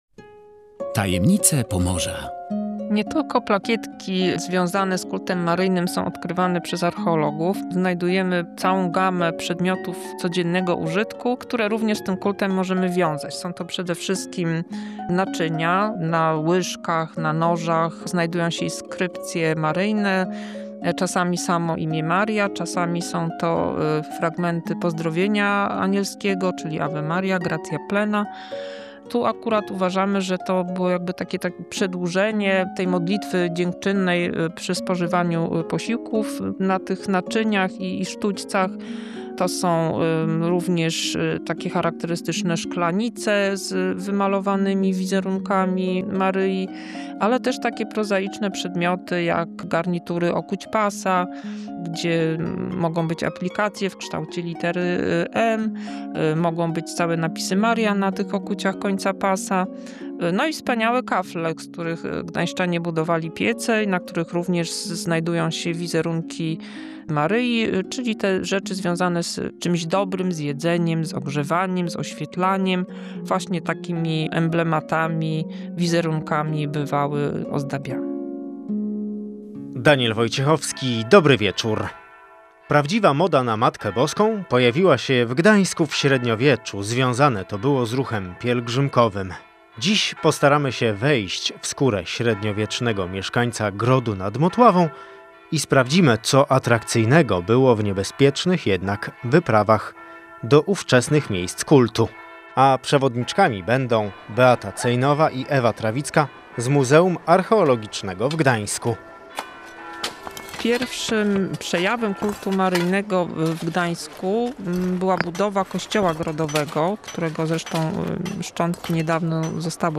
Posłuchaj audycji o ruchu pielgrzymkowym i pielgrzymkowych gadżetach z przeszłości: